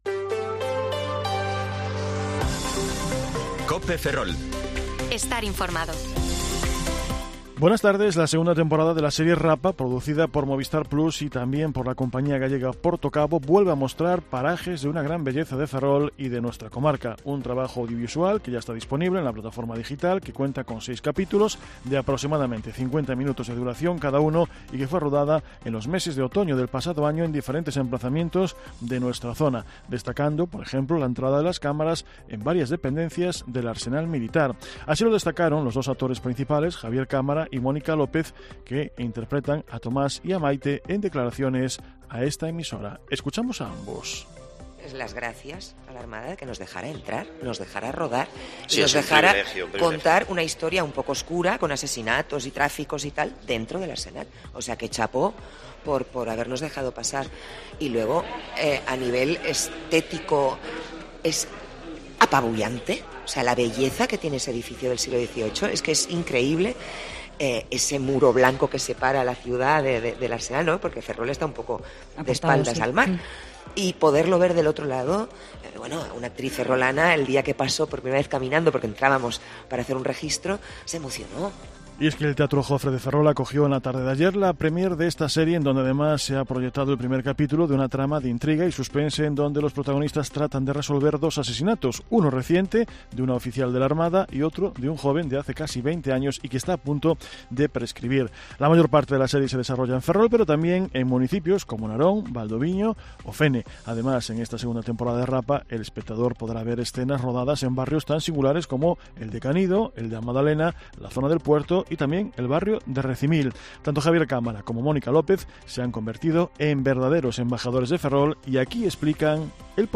Informativo Mediodía COPE Ferrol 15/6/2023 (De 14,20 a 14,30 horas)